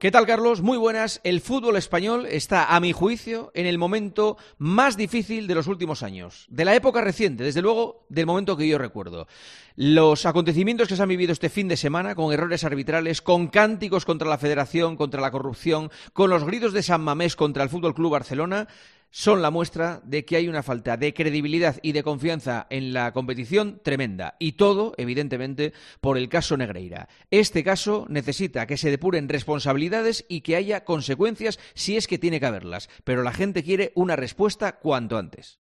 El presentador de 'El Partidazo de COPE' analiza la actualidad deportiva en 'Herrera en COPE'